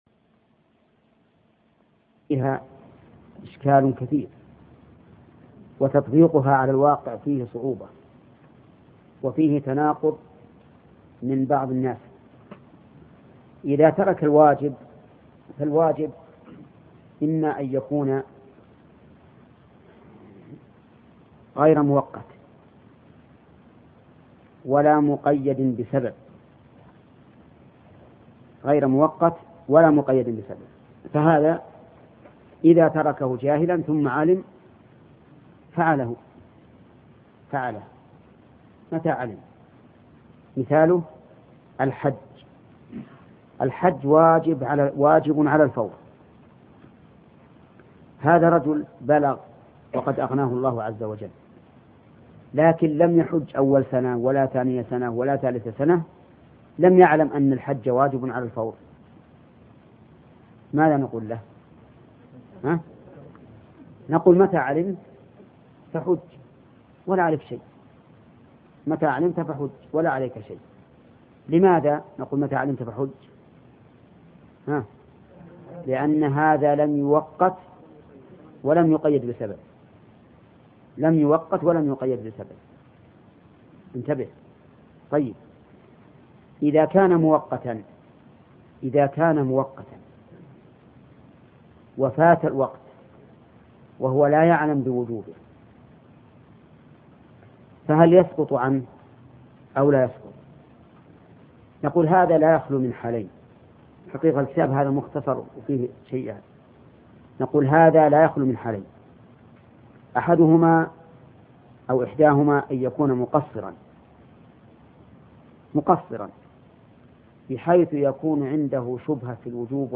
شرح الأصول من علم الأصول الشيخ محمد بن صالح العثيمين